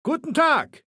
Datei:Maleold01 ms06 hello 000838f7.ogg
Fallout 3: Audiodialoge